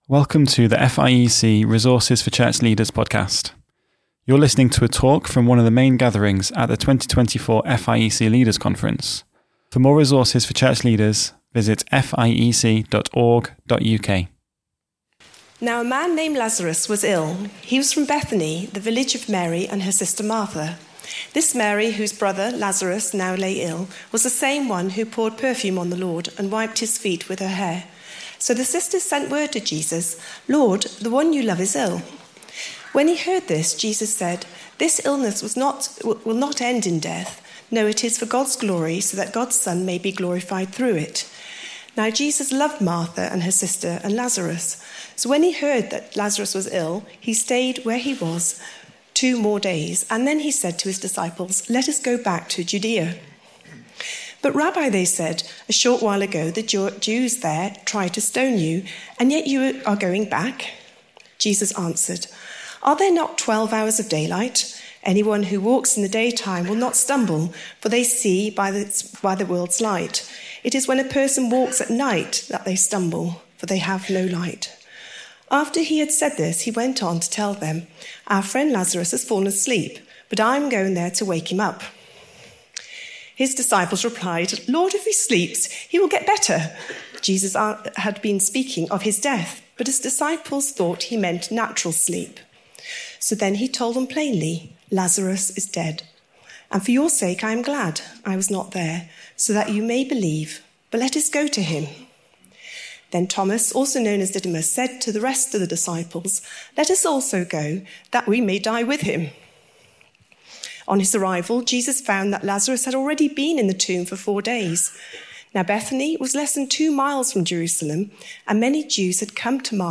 Series: Leaders' Conference 2024